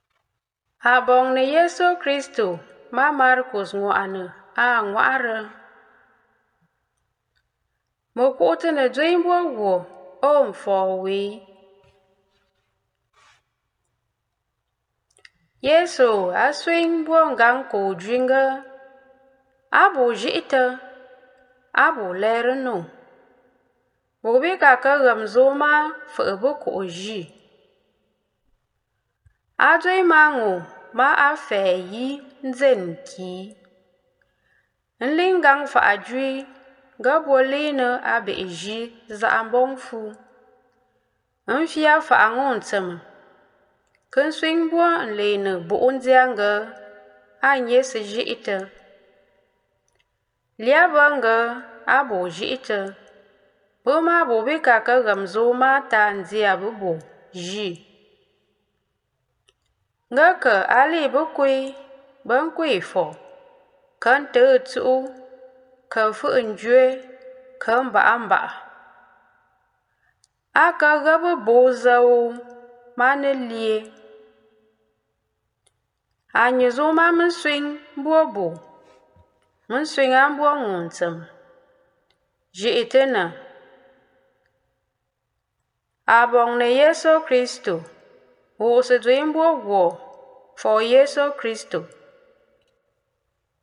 9b80d-gospel_of_first_sunday_of_advent_year_b_in_nkwen.mp3